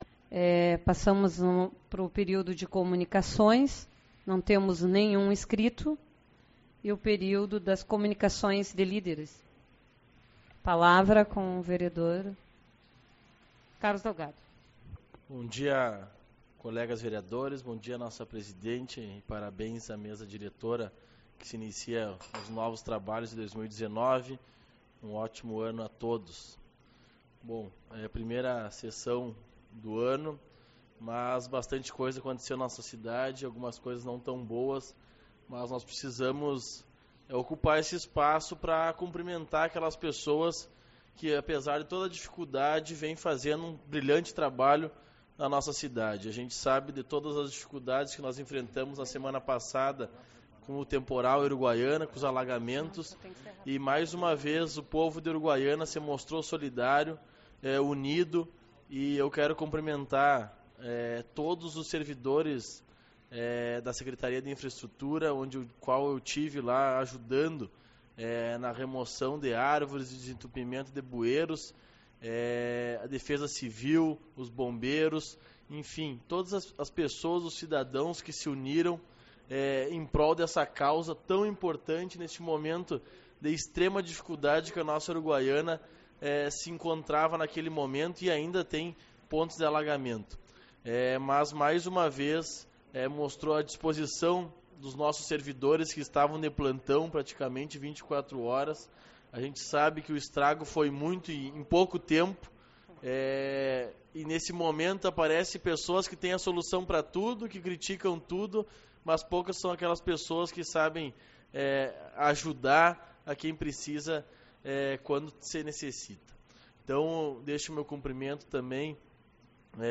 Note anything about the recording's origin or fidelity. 16/01 - Reunião Representativa